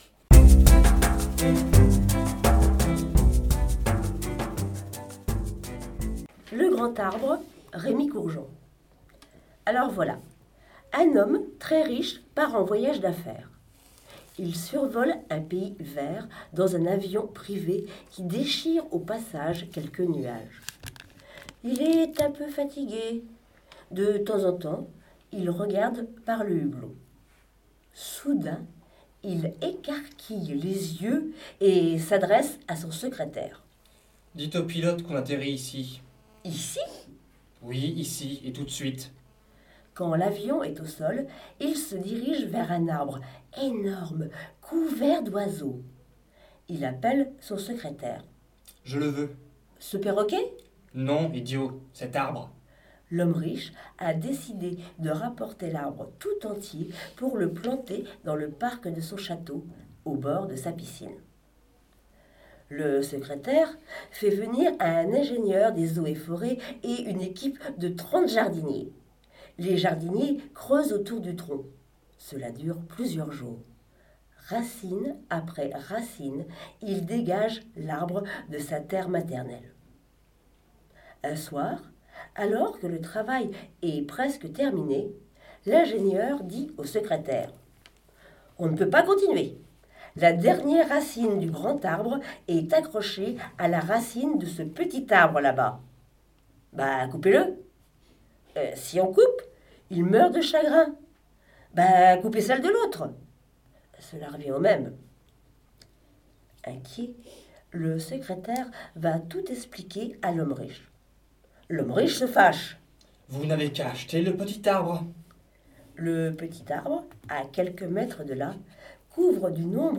Les hauts parleurs de l’association « Lire à Saint-Lô » prêtent leurs voix sur MDR! Cette fois-ci, ils nous lisent les extraits des livres suivants : - « Le grand arbre » de Rémy Courgeon et « Paris L’instant » de Philippe Delerme : « La fille des cimetières »